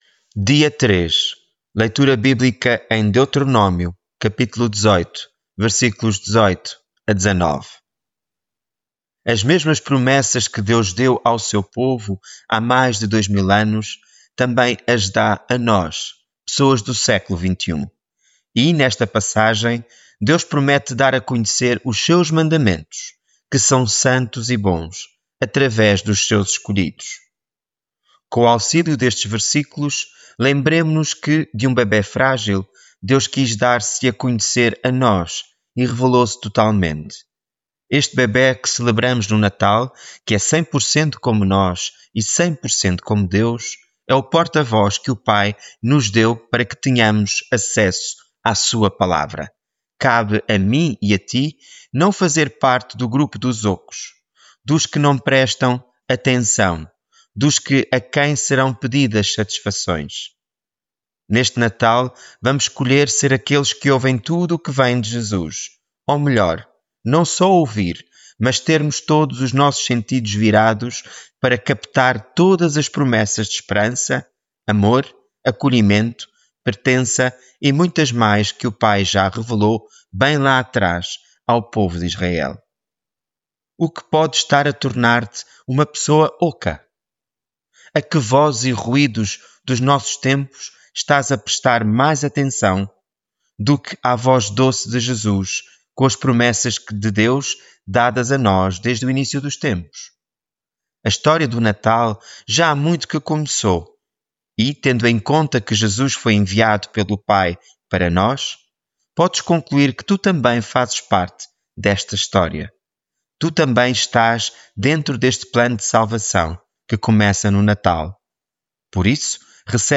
Devocional
Leitura bíblica em Deuteronómio 18:18-19